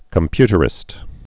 (kəm-pytər-ĭst)